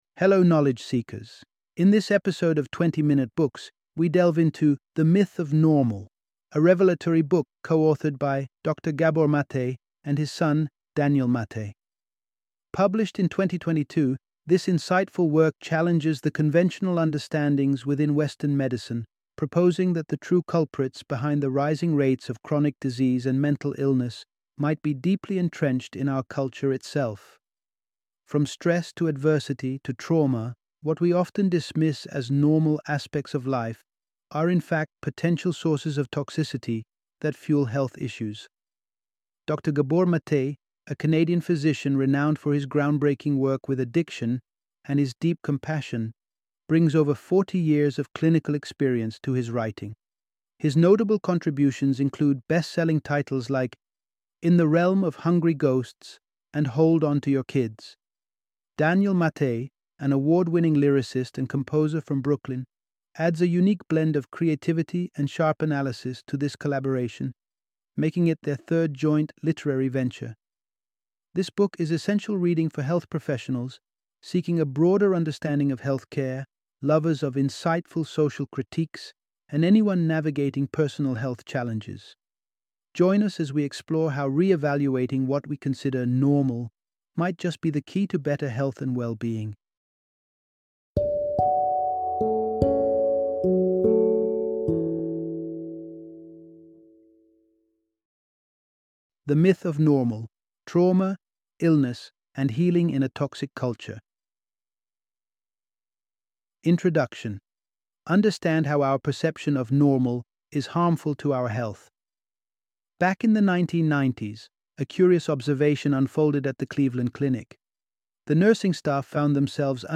The Myth of Normal - Audiobook Summary